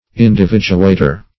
Search Result for " individuator" : The Collaborative International Dictionary of English v.0.48: Individuator \In`di*vid"u*a`tor\, n. One who, or that which, individuates.